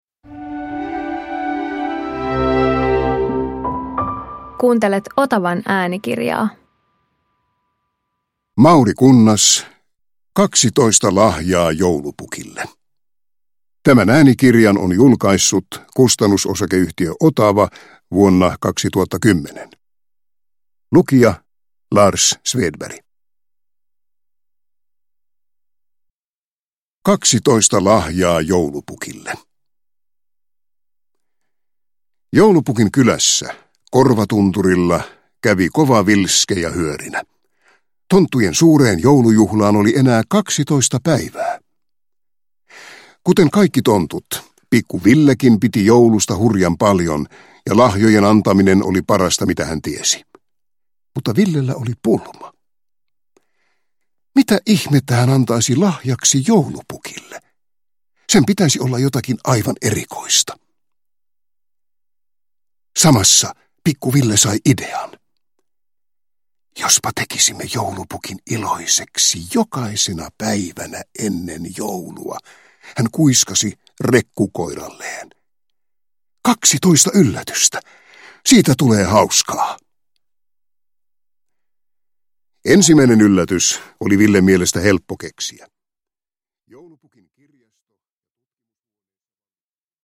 Kaksitoista lahjaa Joulupukille – Ljudbok